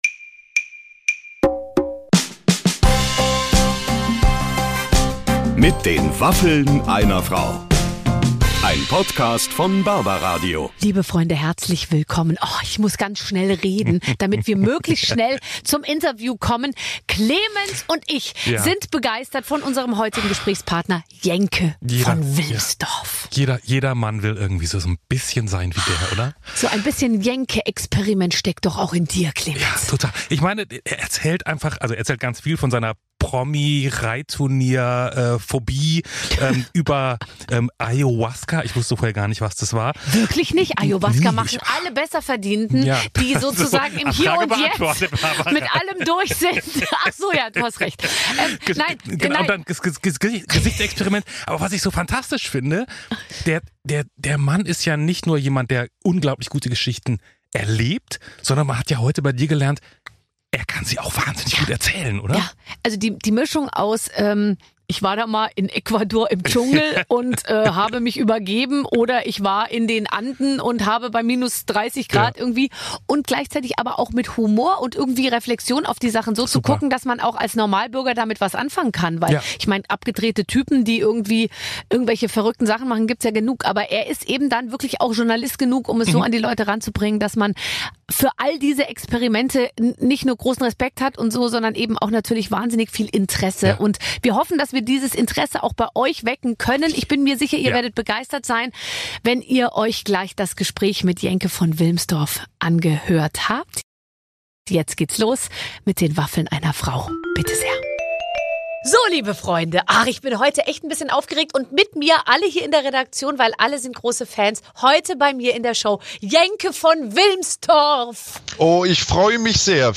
Jenke von Wilmsdorff spricht mit Barbara Schöneberger über seine Jenke-Experimente! Der Journalist verrät, worum es in seinem neuesten Experiment gehen wird. Außerdem erzählt Jenke von Wilmsdorff, warum Entspannung für ihn ein wichtiger Ausgleich ist und warum er sich nach seiner OP nach Angela Merkel erkundigt hat.